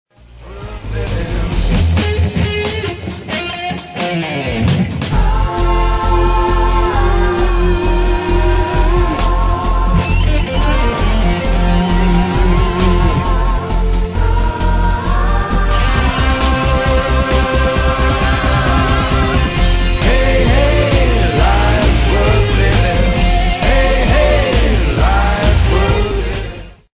vokal pop, gitarski break